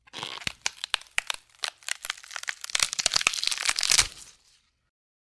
Tiếng Gãy kêu răng rắc
Tiếng Gỗ gãy, bẻ gỗ, gỗ bị nứt… Rắc, Rầm…. Tiếng Chuột lang kêu
Thể loại: Tiếng động
Description: Tiếng gãy vang lên răng rắc, như từng thớ gỗ nứt toác, tách lìa, xé vụn trong cơn chịu lực cuối cùng. Âm thanh khô khốc dội vào không gian, xen lẫn những tiếng lách tách, rắc rắc ghê rợn. Rồi bất chợt, cả kết cấu sập ầm, tiếng rầm chấn động, mảnh gỗ và bụi tung mù, báo hiệu cây cầu đã gãy đổ hoàn toàn.
tieng-gay-keu-rang-rac-www_tiengdong_com.mp3